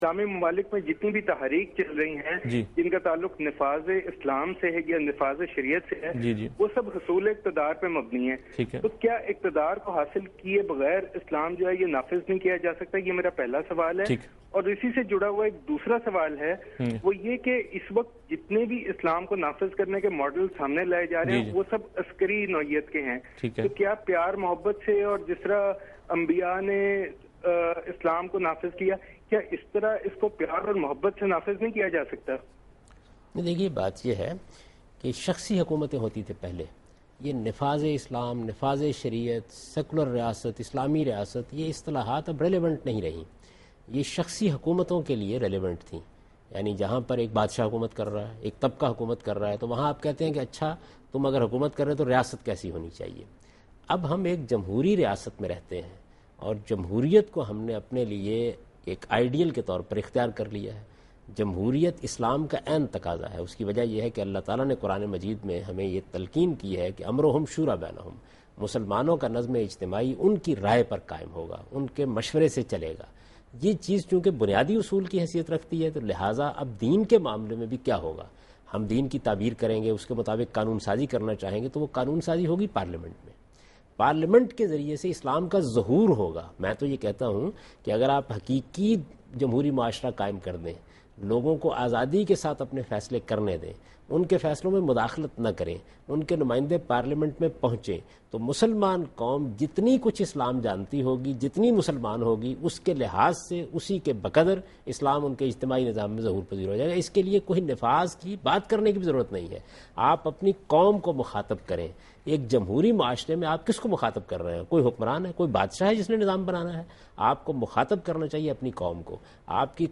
Category: TV Programs / Dunya News / Deen-o-Daanish / Questions_Answers /
Answer to a Question by Javed Ahmad Ghamidi during a talk show "Deen o Danish" on Duny News TV
دنیا نیوز کے پروگرام دین و دانش میں جاوید احمد غامدی ”اسلام میں پردے کے احکام“ سے متعلق ایک سوال کا جواب دے رہے ہیں